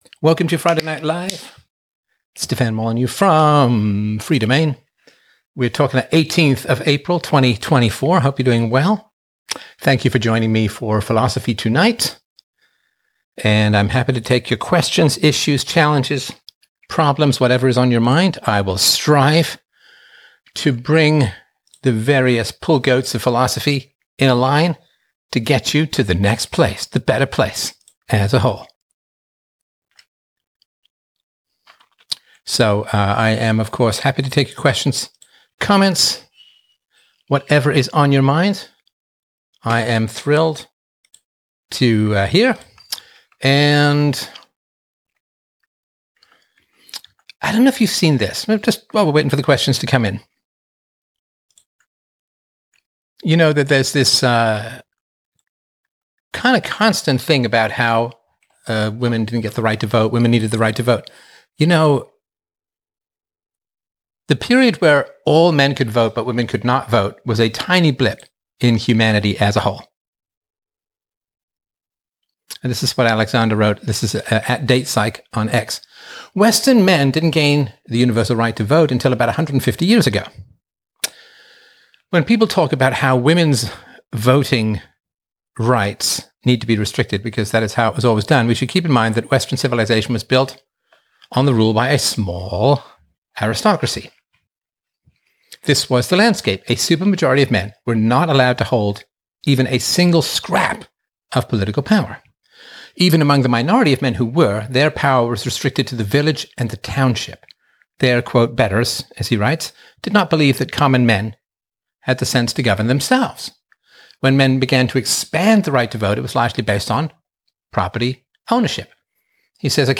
9 April 2025 Wednesday Night Live This episode features a lively Wednesday night discussion, inviting listener engagement on diverse topics. The host highlights a troubling survey revealing that 75% of U.S. scientists may leave the country due to changes in federal research policies, exploring the implications for the scientific community and public trust in science.